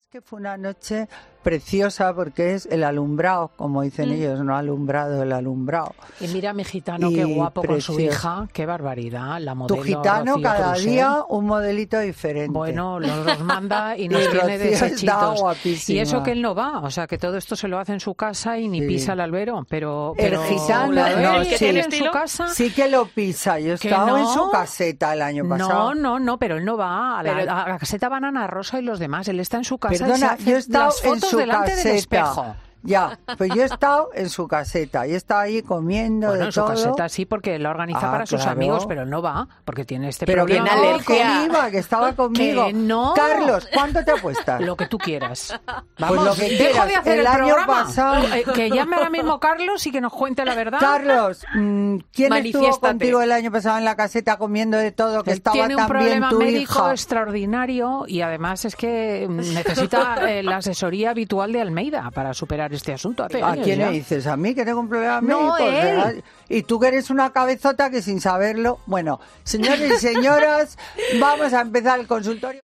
La comunicadora de 'Fin de Semana' y la socialité han protagonizado una divertida discusión por lo que hizo Carlos Herrera en la feria que ha desatado las risas del equipo
Ella decía, en ese mismo instante, "lo que quieras, dejo de hacer el programa. Que llame ahora mismo Carlos y nos cuente la verdad" sentenciaba entre risas, mientras desataba la carcajada entre todos los miembros del equipo.